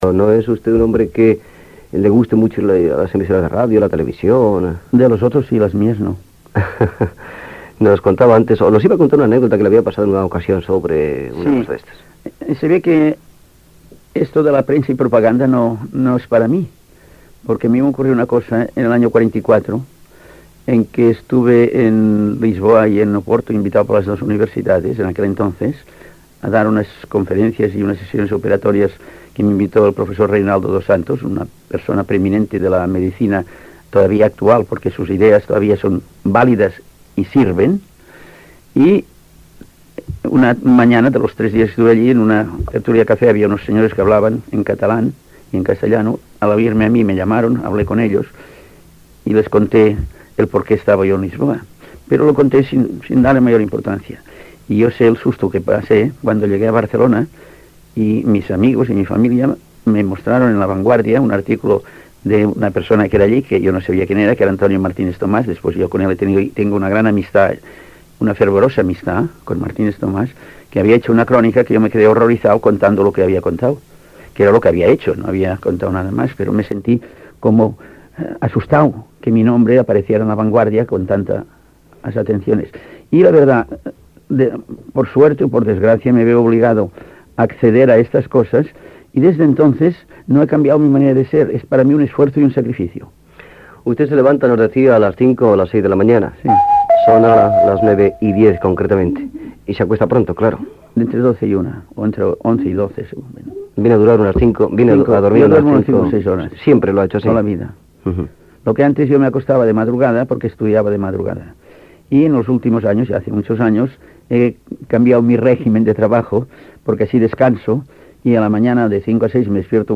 Entrevista al Doctor José Barraquer que explica un fet que li va passar a Lisboa (Portugal), els seus hàbits qüotidians...
Entreteniment